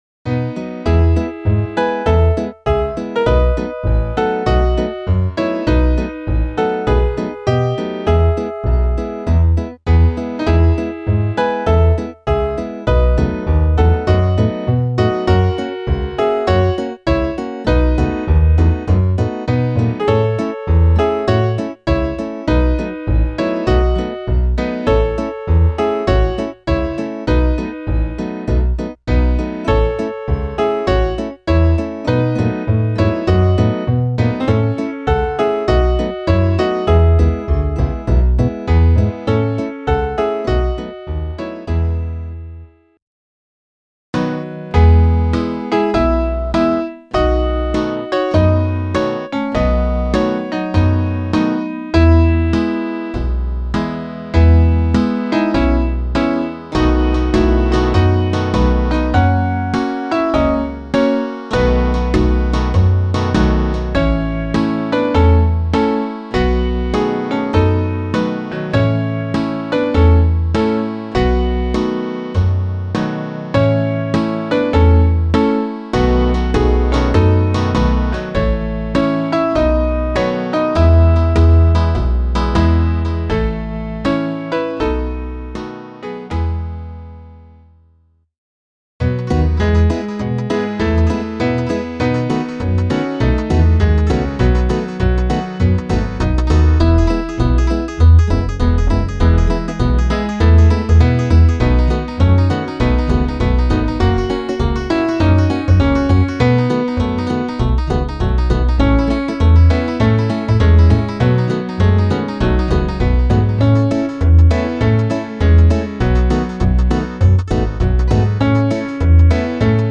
Die Hörprobe stammt von einer Studioaufnahme aus dem Jahr 2018.